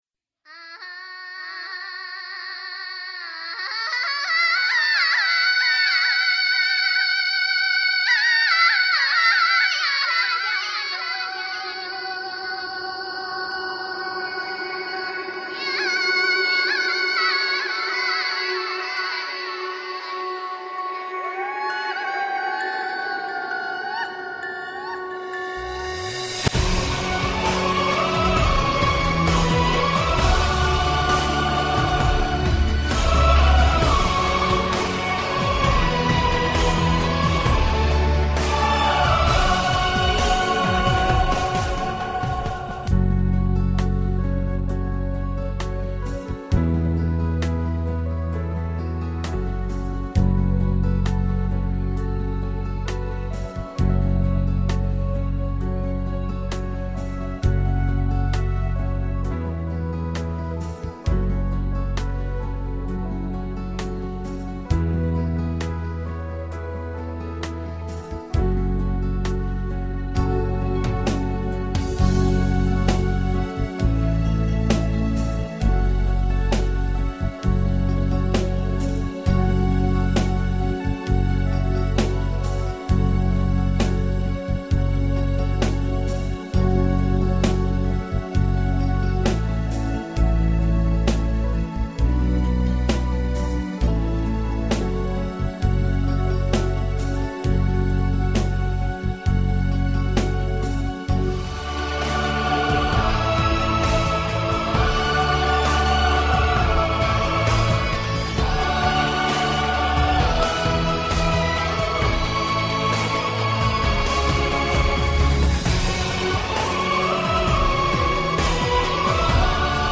伴奏：